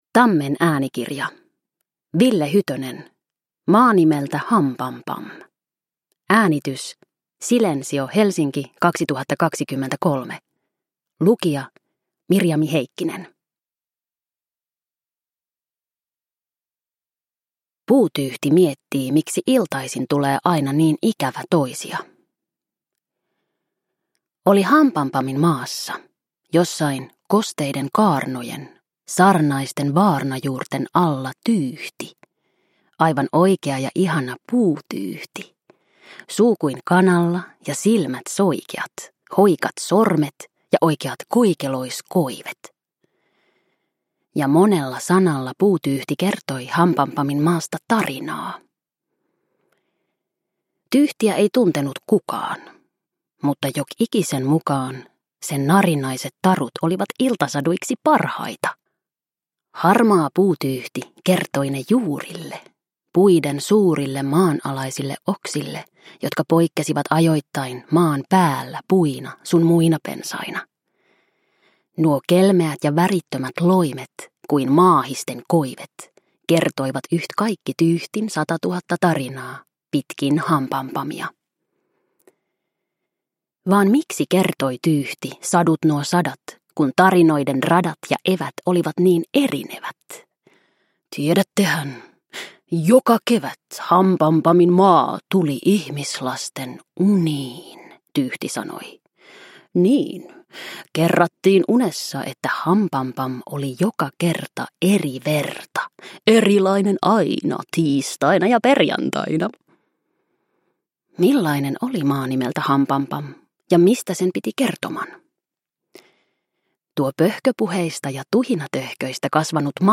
Maa nimeltä Hampampam – Ljudbok